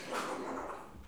bruit-animal_08.wav